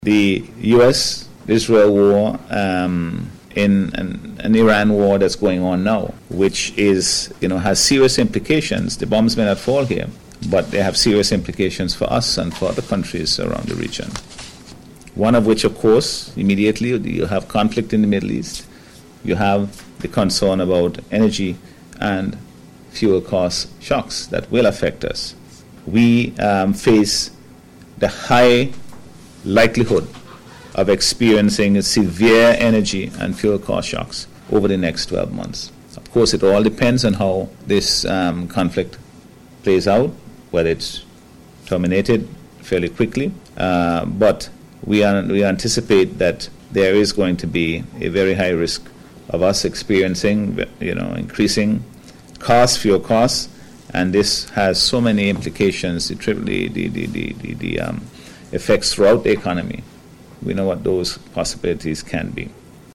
The point was made by Prime Minister Dr. the Hon Godwin Friday, during the News Conference yesterday.